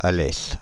Alès (French: [alɛs]
Fr-Alès.ogg.mp3